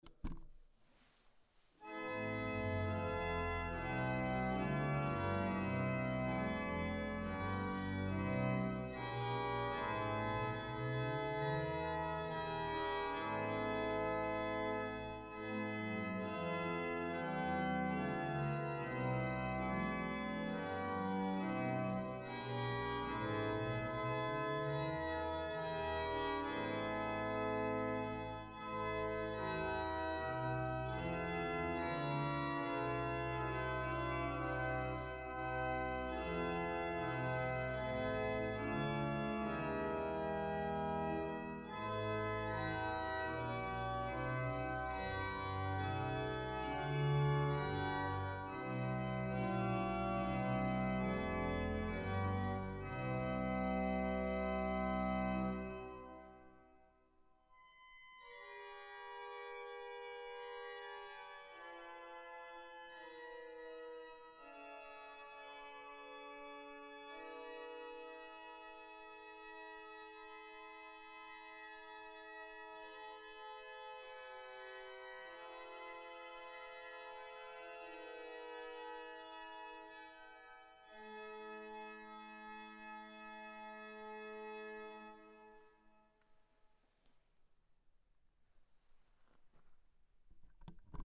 Orgelmusik zur Station: Orgelimprovisation zu „Oh Haupt voll Blut und Wunden EG 85“